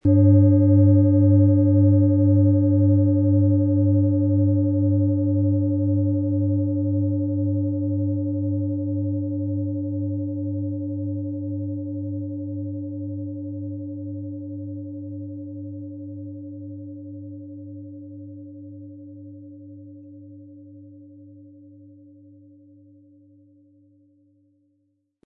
HerstellungIn Handarbeit getrieben
MaterialBronze